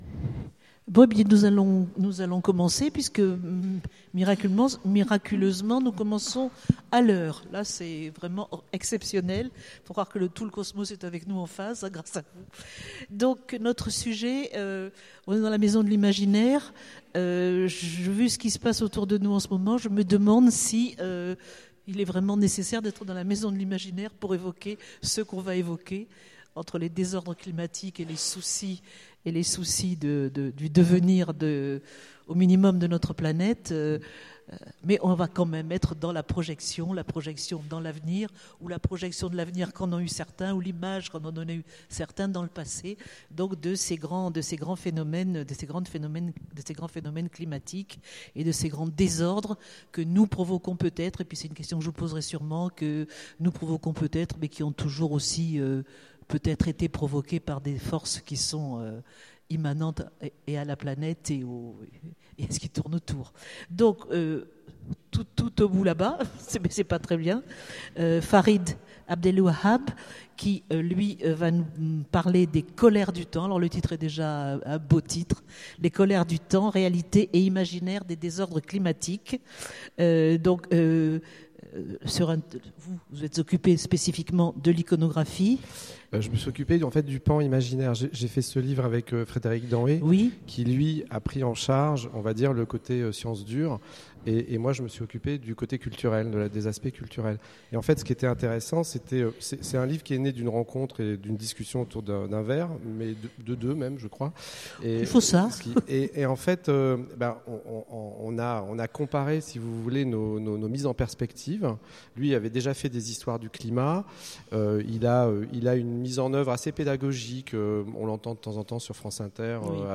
Étonnants Voyageurs : Conférence Réalités et imaginaires des désordres climatiques